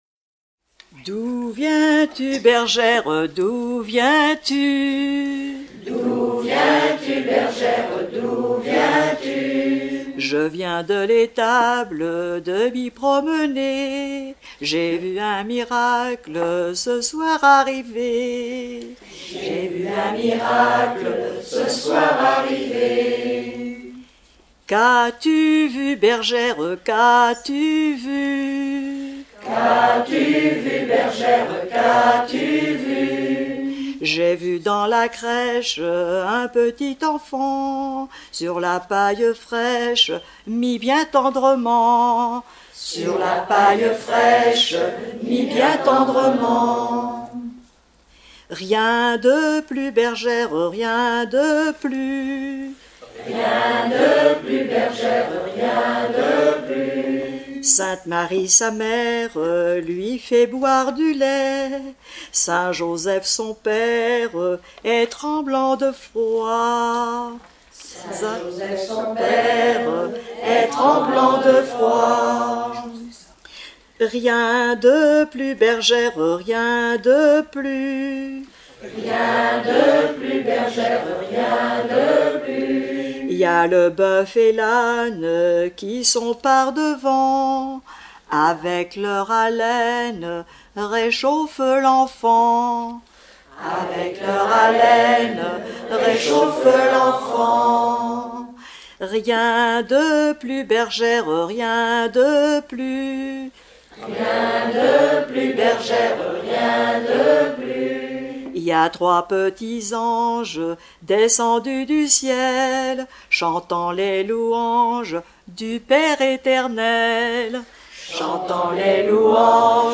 Noël
Genre dialogue